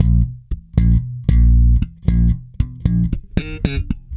-JP THUMB A#.wav